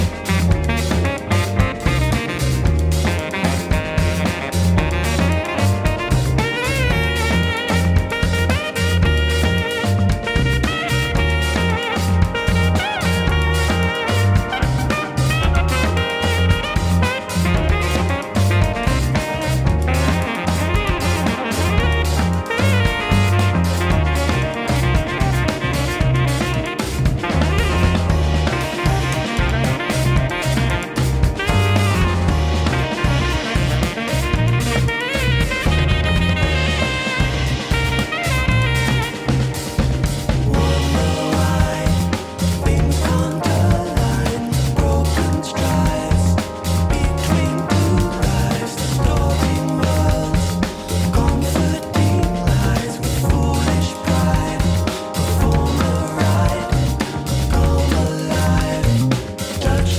Format: LP